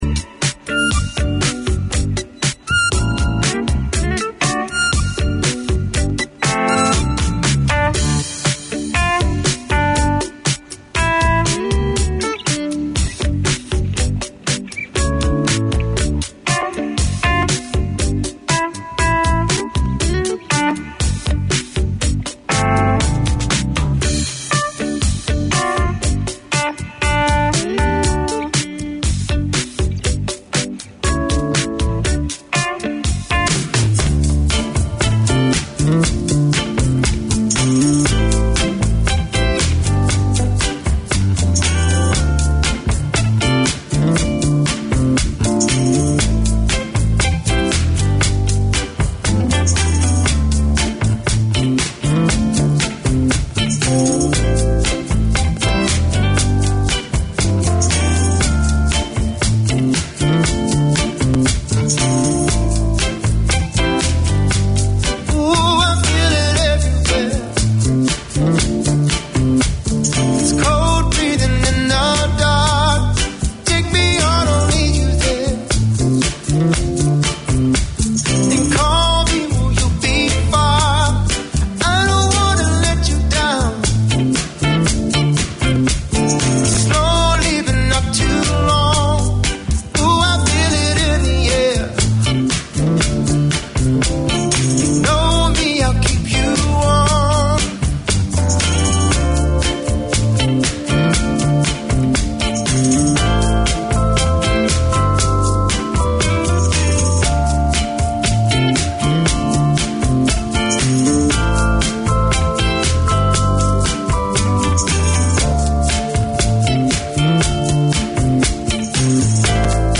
Unfiltered Conversations: Chai & Chat, is a safe and open space where young South Asian girls, can openly discuss, debate, and ask questions about relationships, culture, identity, and everything in between. They are on-air to break the stigma, share experiences, and support each other through honest conversations over a cup of chai.